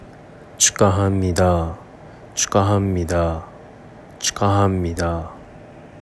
チュカハンニダ
■축하합니다（チュカハンニダ）の発音